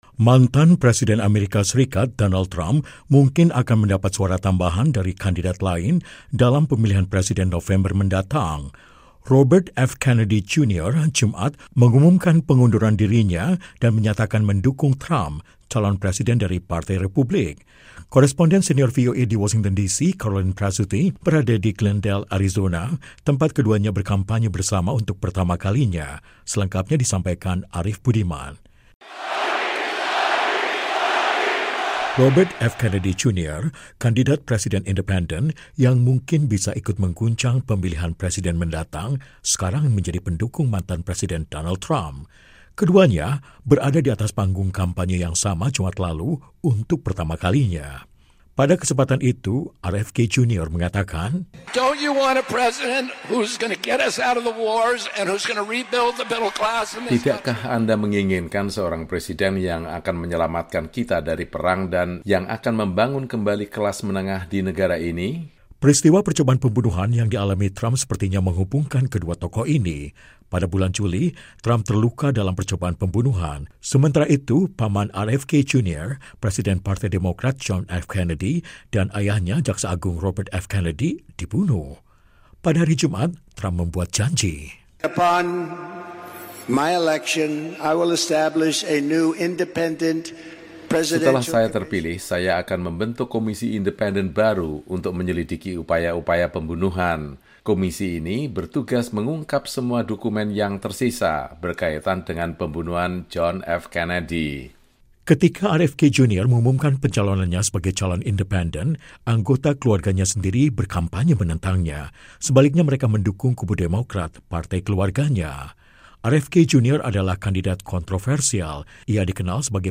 Capres Partai Republik, Donald Trump berjabat tangan dengan mantan kandidat presiden independen Robert F. Kennedy Jr. (kiri), selama rapat umum di Glendale, Arizona, AS, 23 Agustus 2024.